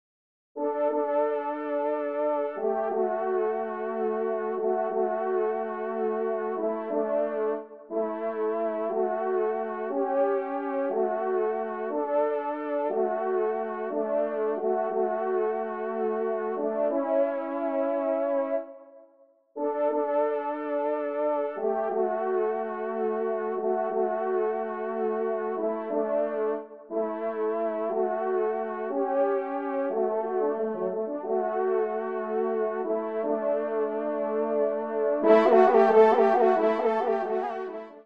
Genre : Musique Religieuse pour  Quatre Trompes ou Cors
Pupitre 3° Trompe